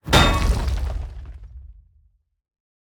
Minecraft Version Minecraft Version snapshot Latest Release | Latest Snapshot snapshot / assets / minecraft / sounds / item / mace / smash_ground4.ogg Compare With Compare With Latest Release | Latest Snapshot
smash_ground4.ogg